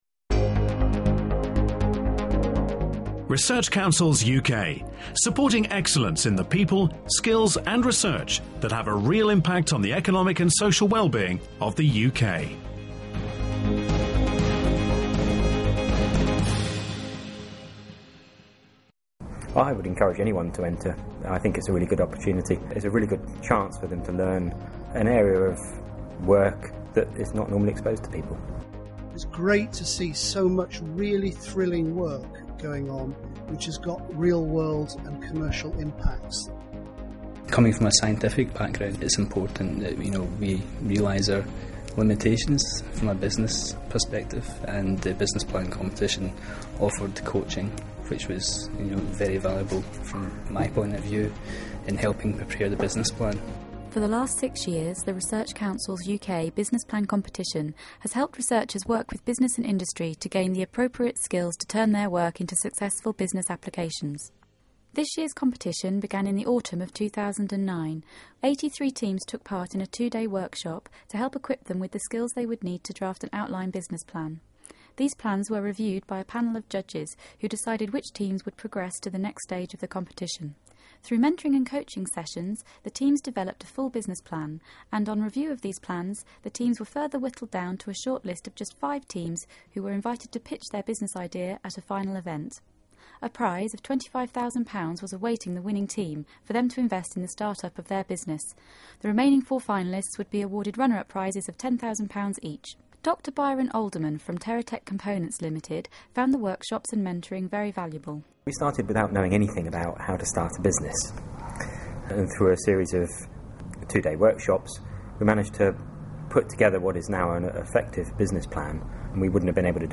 To find out more about the RCUK Business Plan Competition and the impact it had on participants listen to a new podcast featuring interviews with the winners SQUEASE Ltd, other finalists and speakers at the final event.